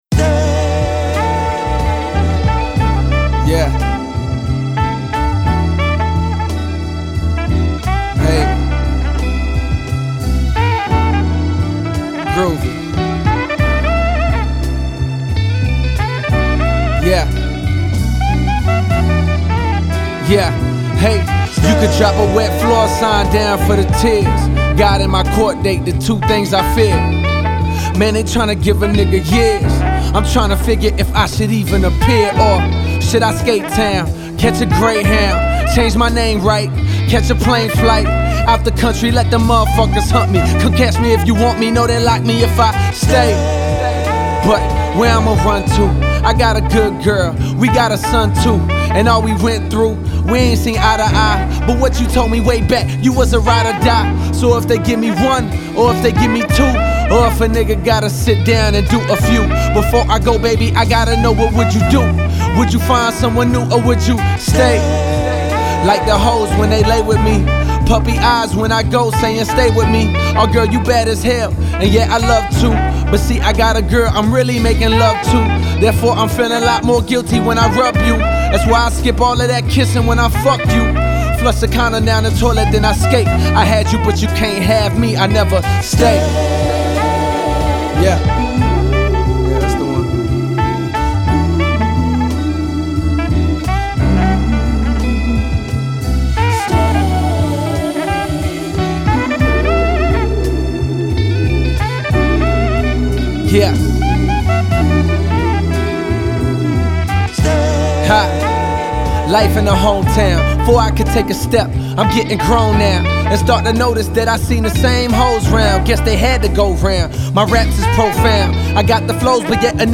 Genero: Hip-Hop/Rap